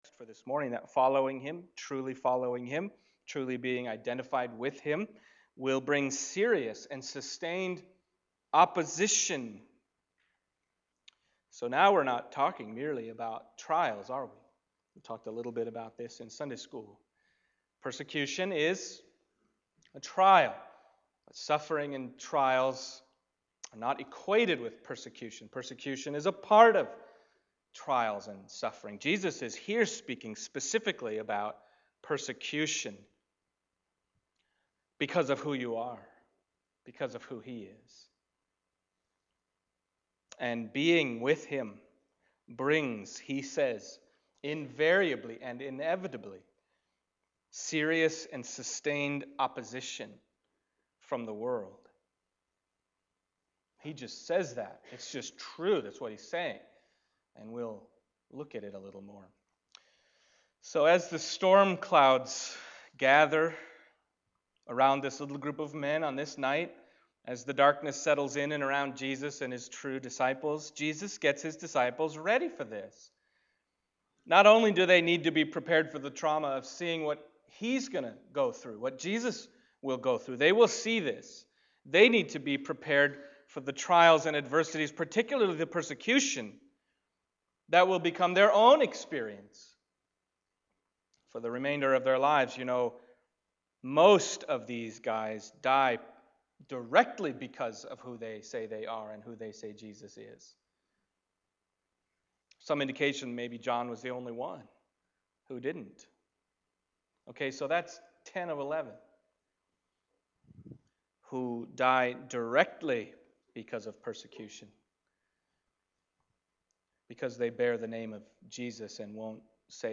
John Passage: John 15:18-25 Service Type: Sunday Morning John 15:18-25 « Greater Love Has No One Than This…